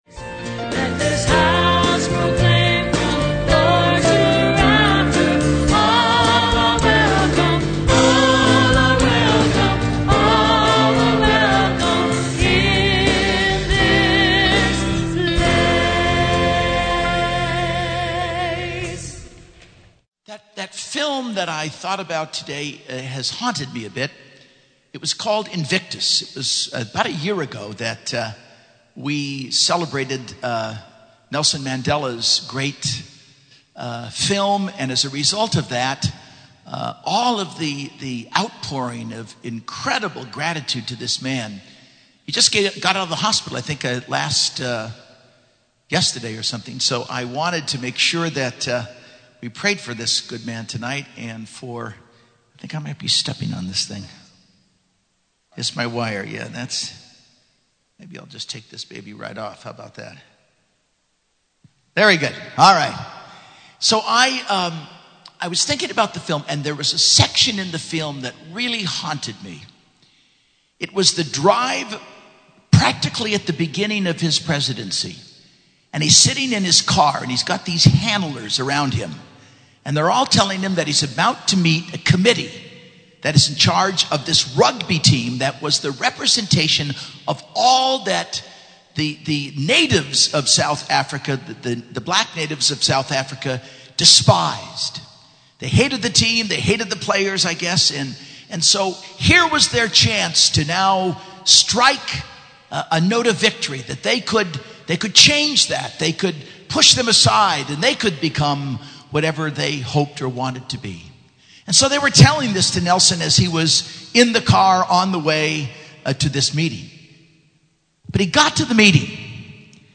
Homily - 1/30/11 - 4th Sunday Ordinary Time